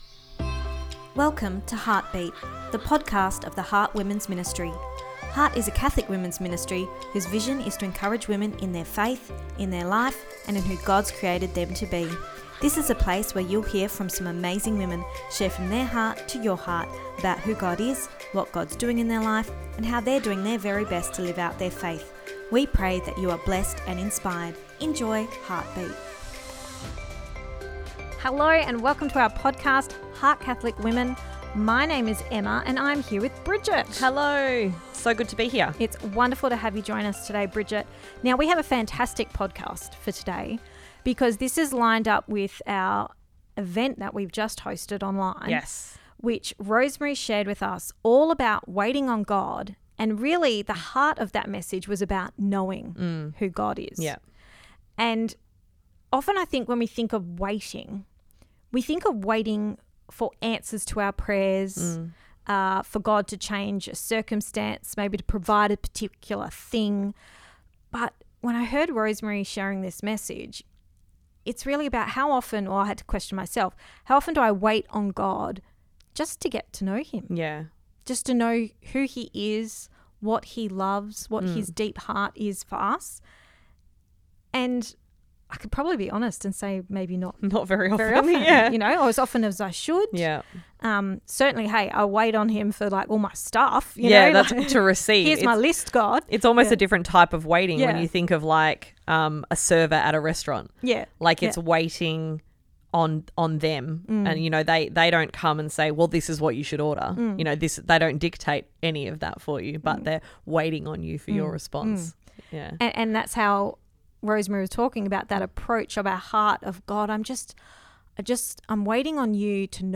Ep235 Pt2 (Our Chat) – Knowing God